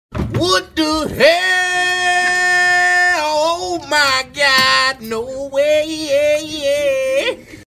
Category: Meme sound
dramatic sound effect